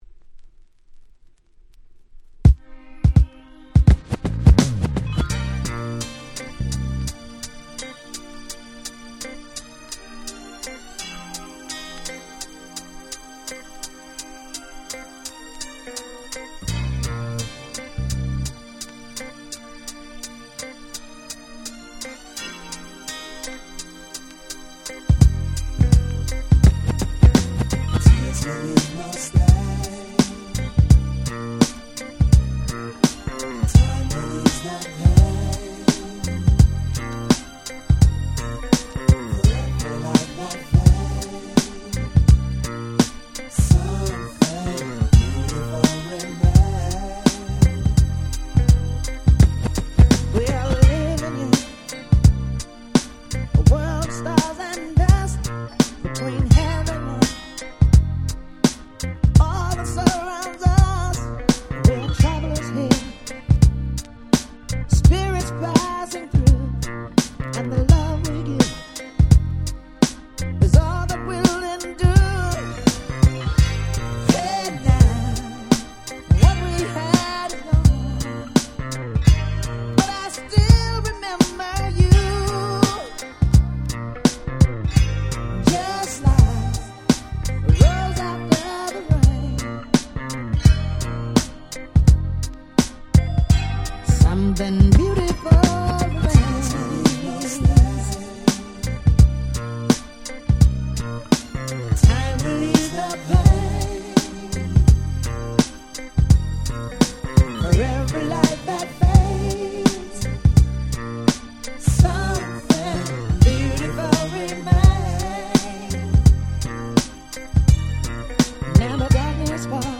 96' Nice Very Nice R&B / Hip Hop Soul !!
Hip Hop Soulに対するUKからの返答、と言わんばかりのUSマナーな教科書通りのヒップホップソウル！！